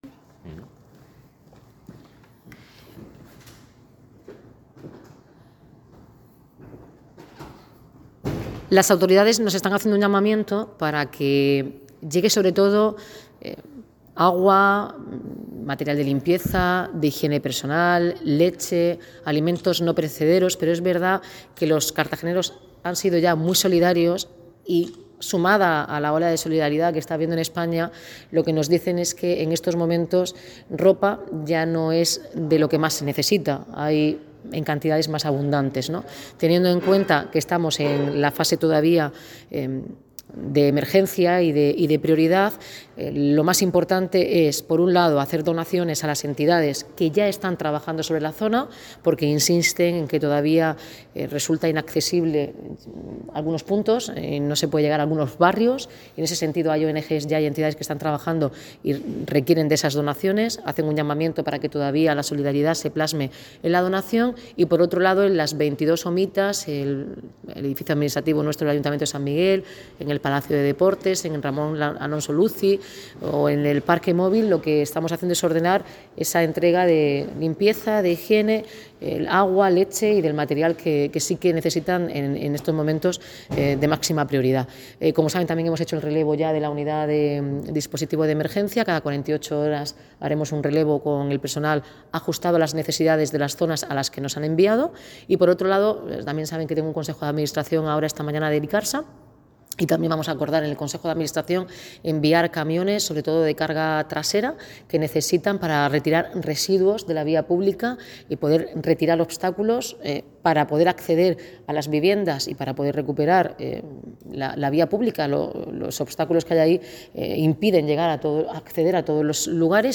Audio: Declaraciones de la alcaldesa, Noelia Arroyo, sobre relevo del contingente de Valencia (MP3 - 723,16 KB)